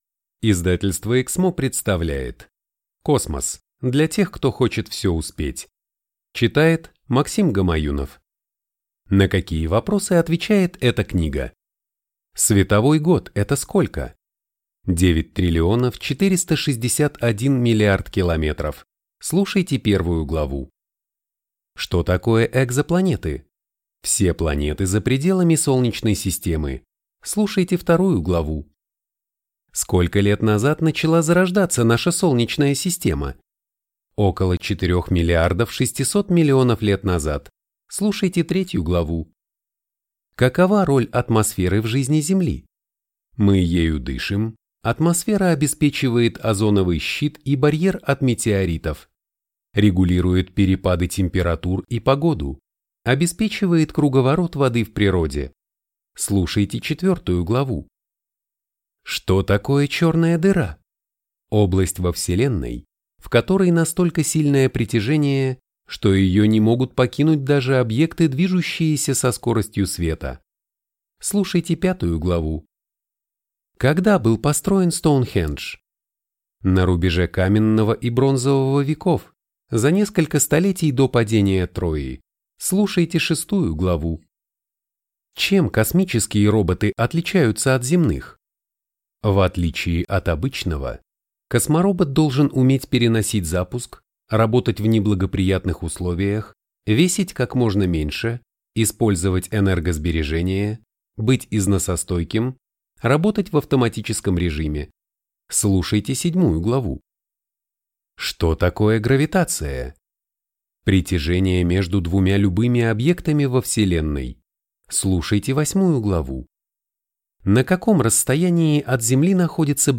Аудиокнига Космос. Для тех, кто хочет все успеть | Библиотека аудиокниг
Прослушать и бесплатно скачать фрагмент аудиокниги